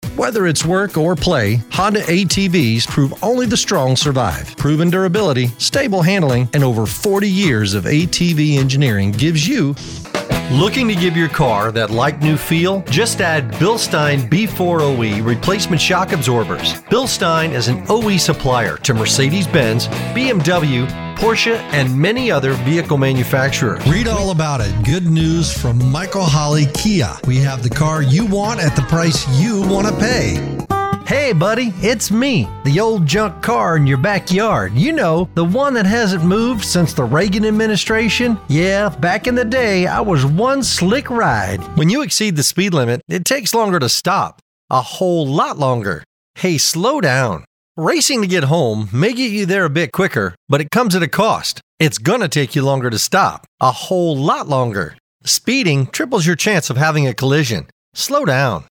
Friendly, Warm, Conversational.
Automotive